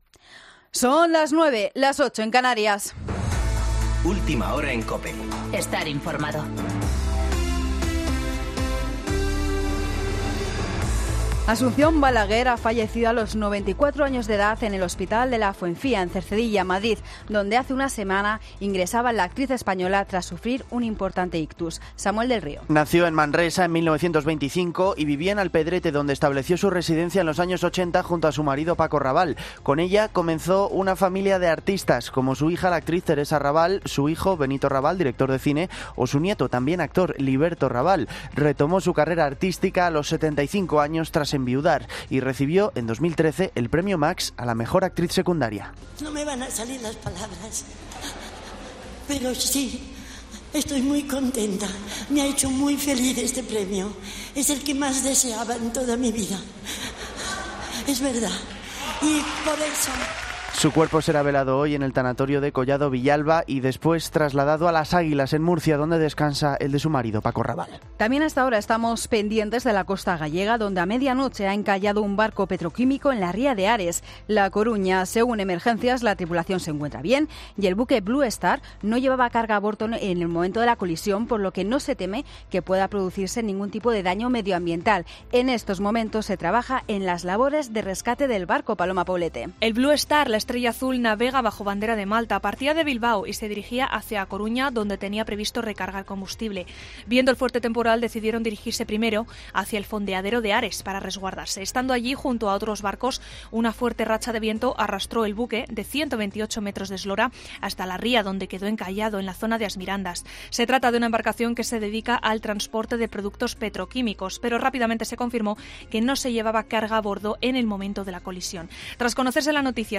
Boletín de noticias COPE del 23 de noviembre de 2019 a las 09.00 horas